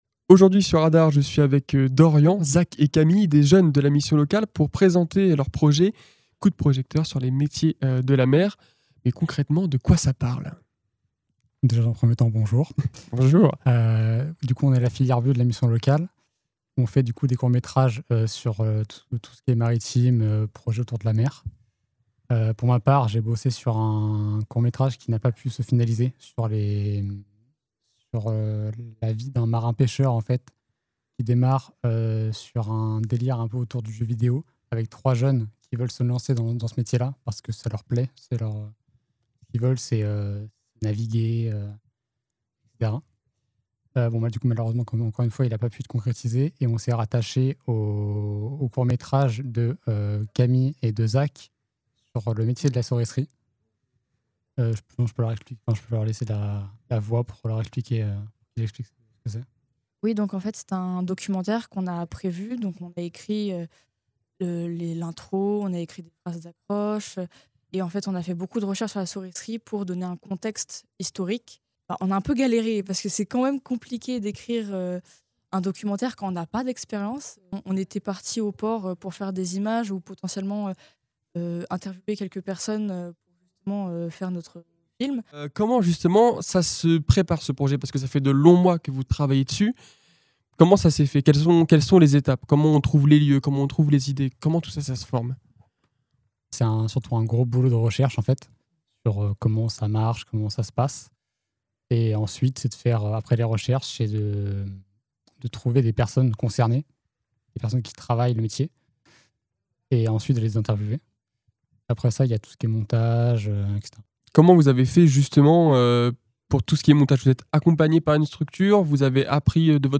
Ils sont trois au micro de Radar.
C’est ce que l’on va découvrir dans cette interview made in RADAR !